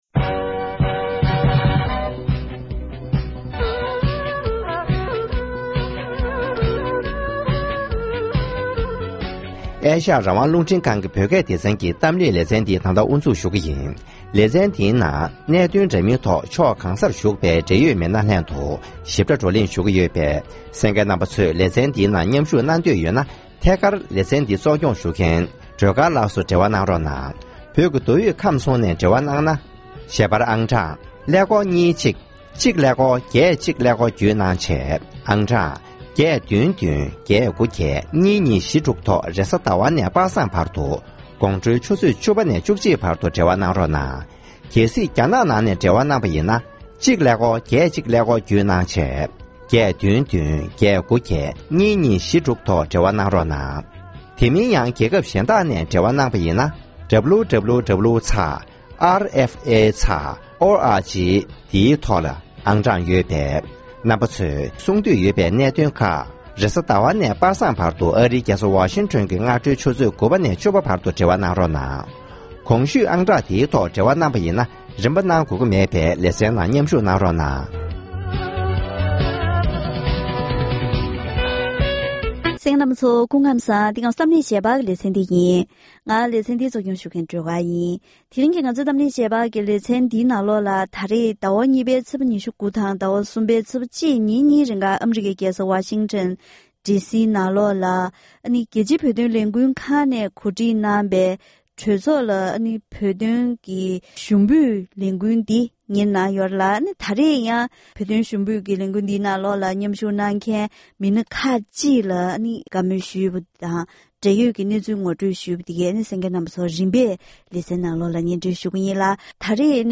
༄༅། །དེ་རིང་གི་གཏམ་གླེང་ཞལ་པར་ལེ་ཚན་ནང་རྒྱལ་སྤྱིའི་བོད་དོན་ལས་འགུལ་ཁང་ནས་གོ་སྒྲིག་གནང་བའི་ཨ་རིའི་གྲོས་ཚོགས་སུ་བོད་དོན་ཞུ་འབོད་ཐེངས་བརྒྱད་པ་འདི་གནང་ཡོད་པའི་སྐོར་དང་། ཞུ་འབོད་ནང་མཉམ་བཞུགས་གནང་མཁན་ཨ་རིའི་བོད་མི་ཁག་ཅིག་ནས་རང་ཉིད་གནས་ཡུལ་ས་གནས་ཀྱི་གྲོས་ཚོགས་འཐུས་མི་དང་ལས་བྱེད་མཇལ་ཏེ་བོད་དོན་གླེང་བའི་སྐོར་བཀའ་མོལ་ཞུས་པ་ཞིག་གསན་རོགས་གནང་།